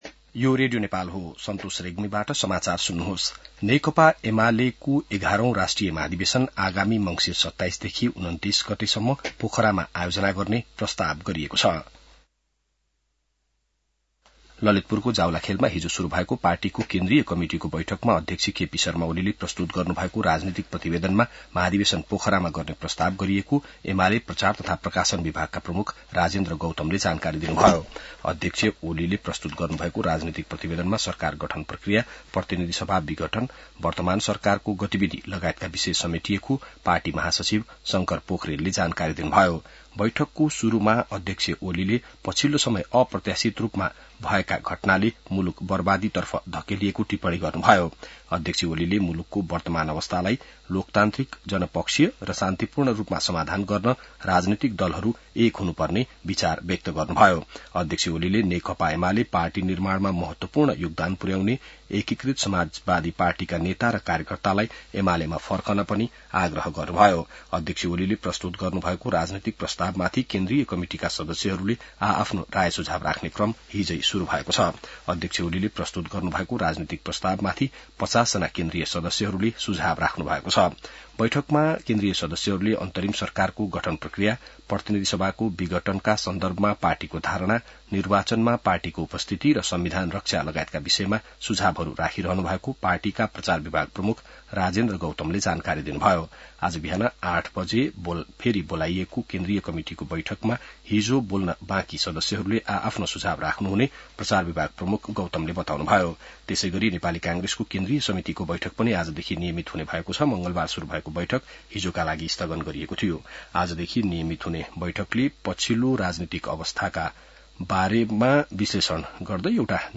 An online outlet of Nepal's national radio broadcaster
बिहान ६ बजेको नेपाली समाचार : ३० असोज , २०८२